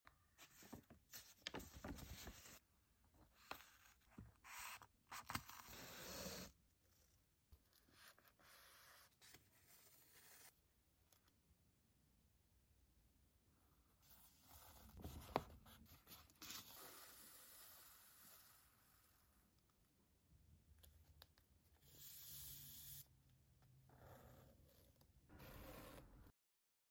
The Peaceful Sounds Of Sewing Sound Effects Free Download
The peaceful sounds of sewing an old book back together after repairing the sections